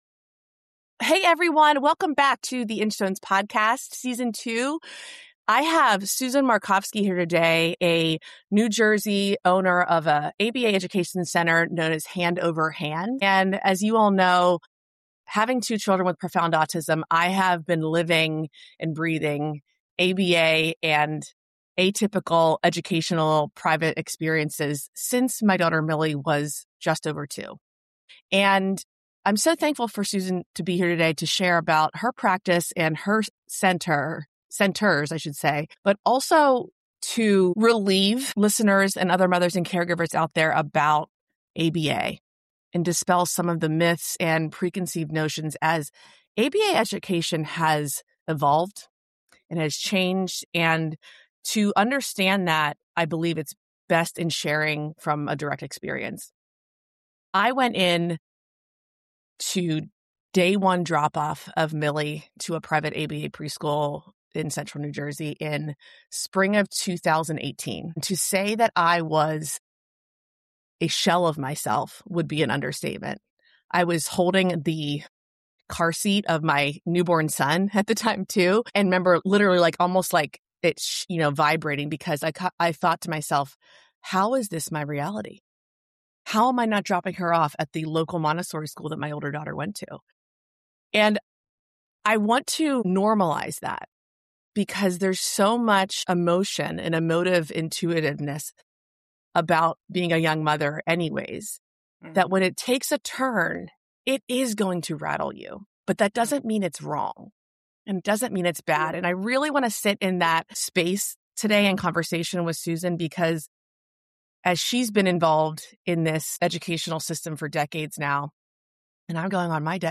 Rather than defending or dismissing, this dialogue focuses on nuance. Autism education is not one-size-fits-all.